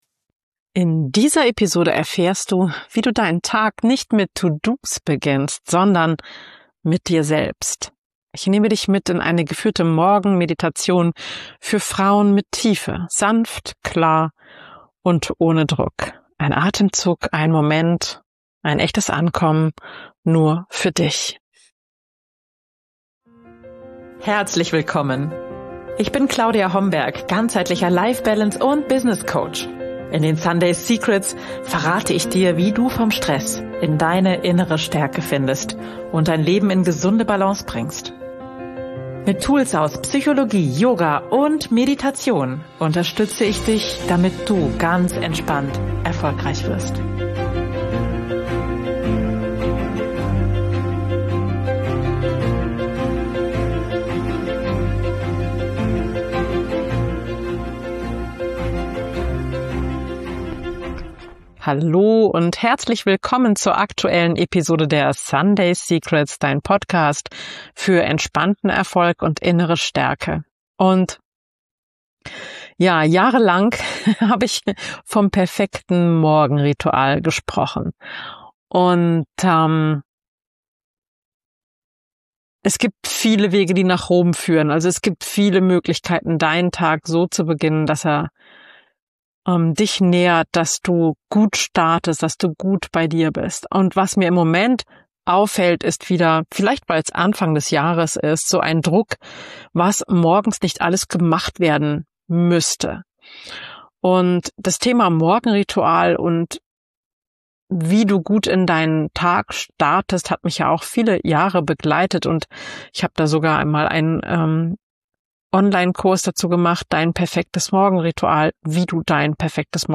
Morgenritual mit Gefühl: Geführte Meditation für Frauen mit Tiefe ~ Sunday Secrets – Midlife, Wandel & Selbstbestimmung Podcast
Wir atmen gemeinsam.